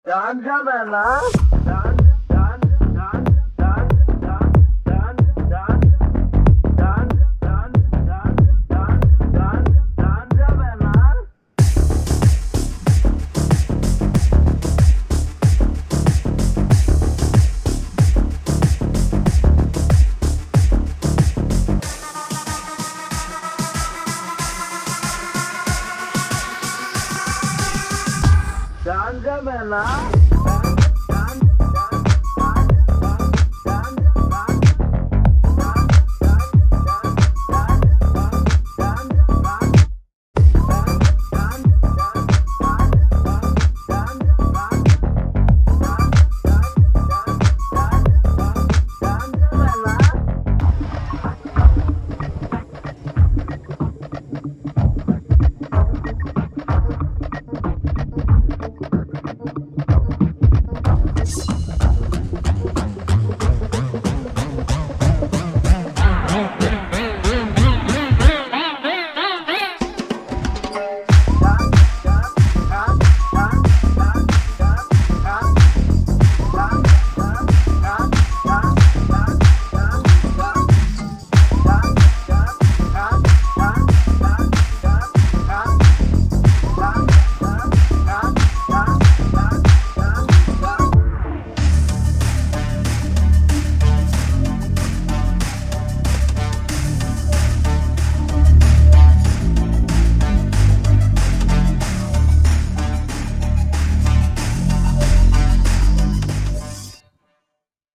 without dialogues and fight sounds